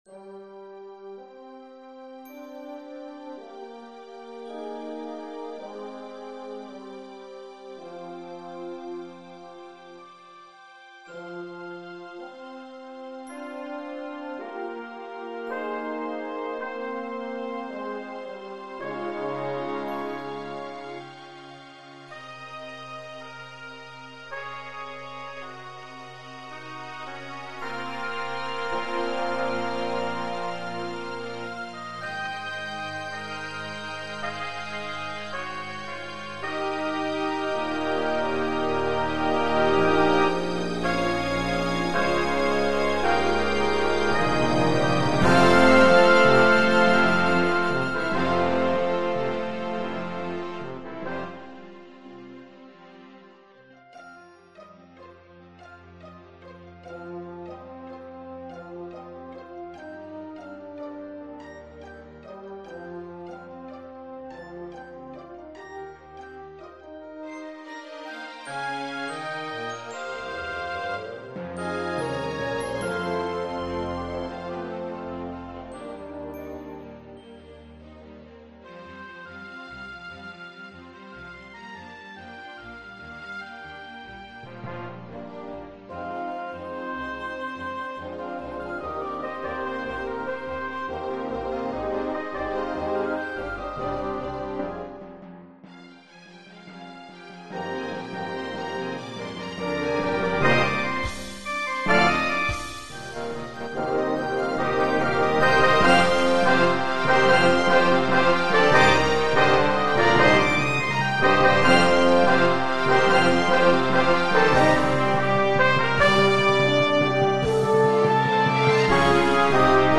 The result is a dynamic and brassy work with a dignified, hymn-like middle section leading to a boisterous 7/8 conclusion.
Gabrielli's Voice An overture based on a fragment by Gabrielli for symphony orchestra.
Instrumentation: picc.2.2.2.bcl.2 / 4.3.3.1 / 3+T strings Composition Date: July 2003 View an extract from the score Listen to a sample recording